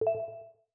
Mellow Message Ping.wav